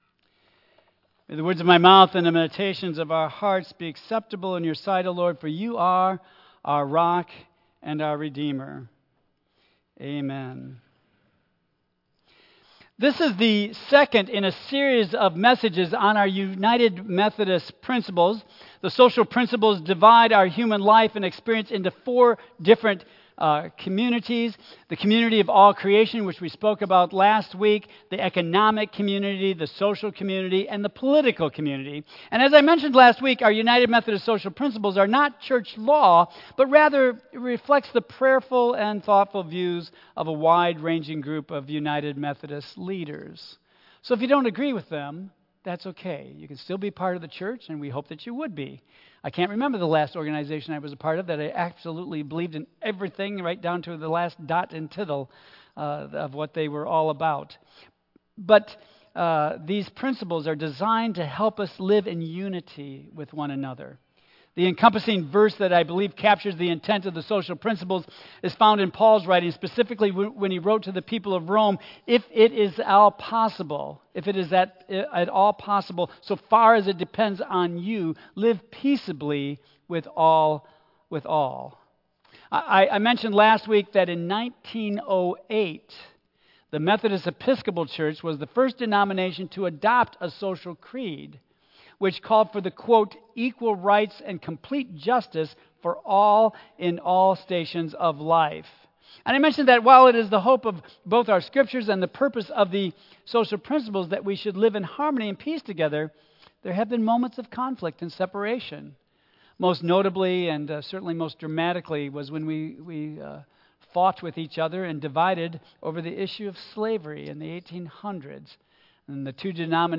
Our UM Social Principles Message Series As we continue our four-part Message Series on Our UM Social Principles, we will look at society and examine ourselves through God’s eyes. God created us for love of one another, and as we seek to live into our identity as being created in God’s image, we must make ourselves aware of each other’s needs and God’s constant guidance for our relationships. Based on Philippians 2:1-8 and Luke 13:31-35.